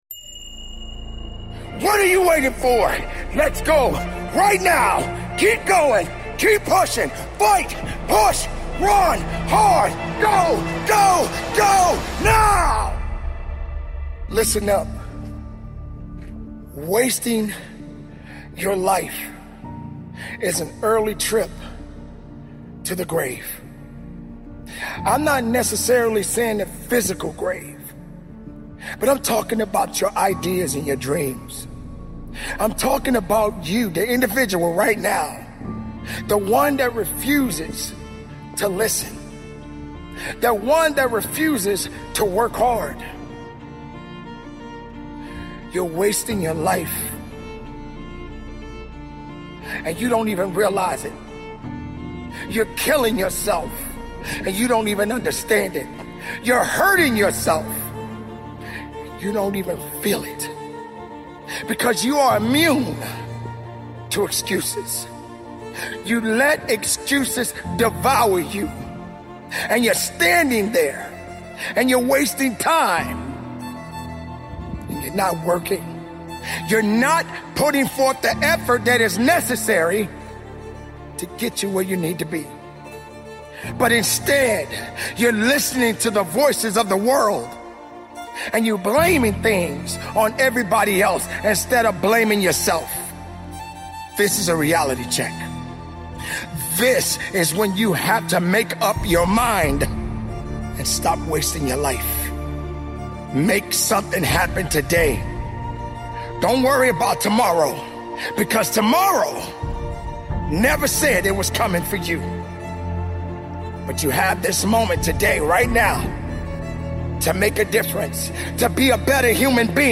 Motivational Speeches